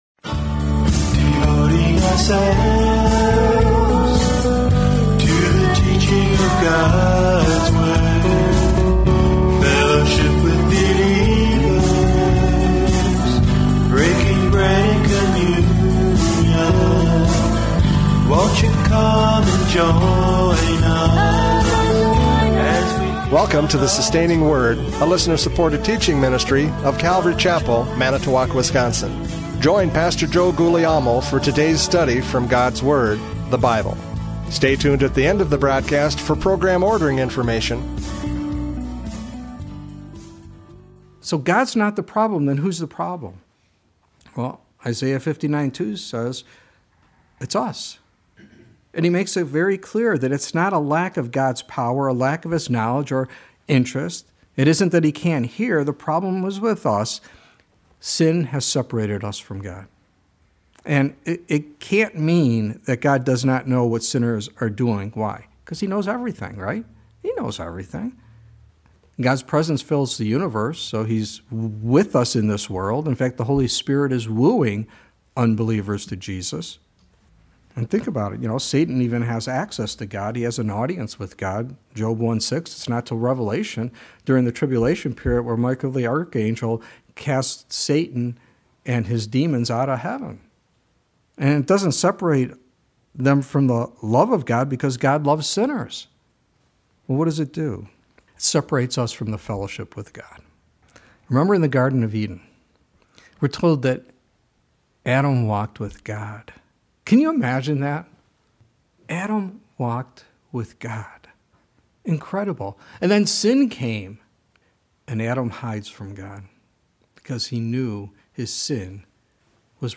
Hebrews 10:5-10 Service Type: Radio Programs « Christmas 2024 Hebrews 10:5-10 Tour of Duty!